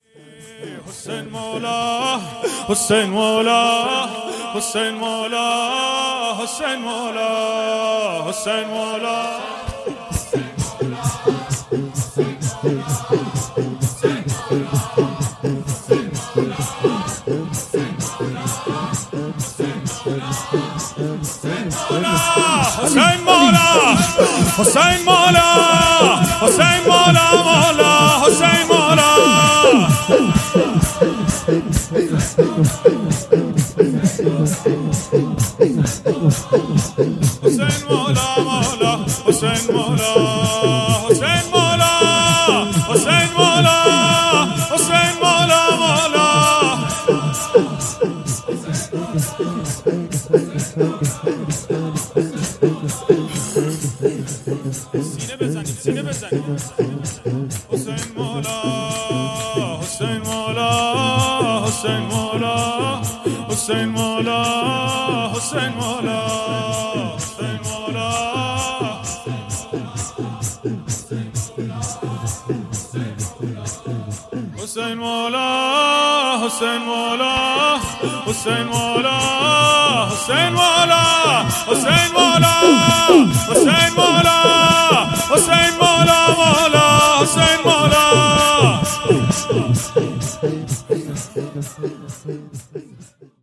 شور | حسین مولا